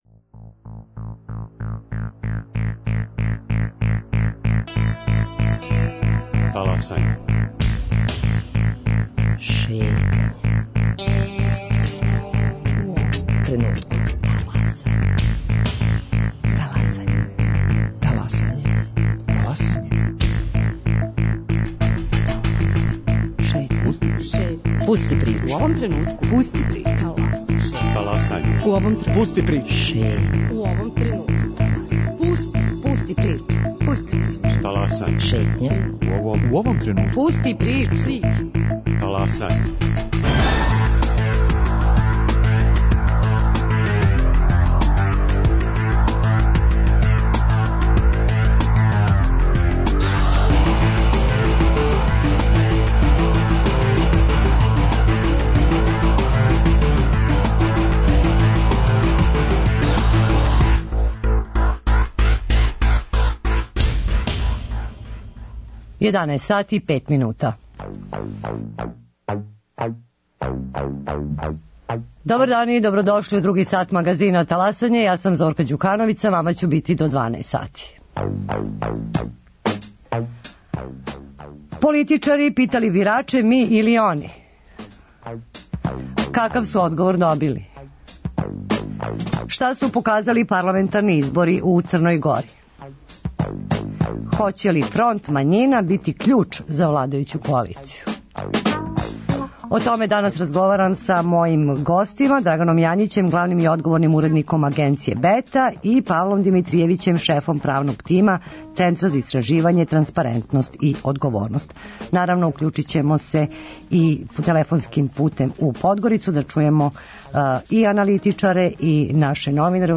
За Таласање говоре аналитичари из Подгорице и гости у студију